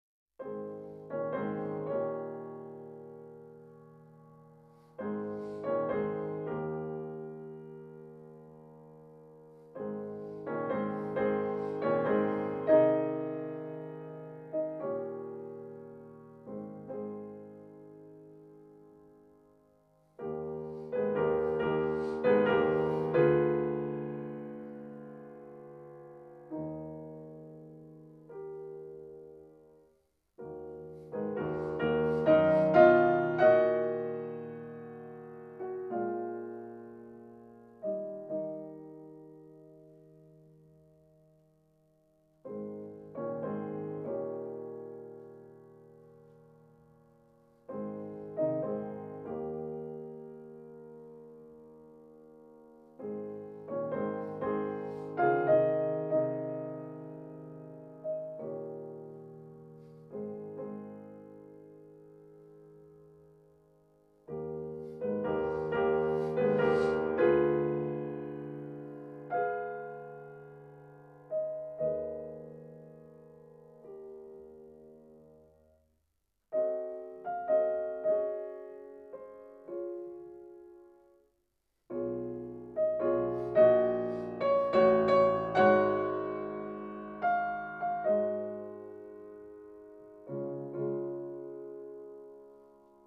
piano
2  As-dur Andantino.mp3